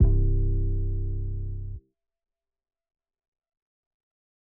12kb - tlc 808.wav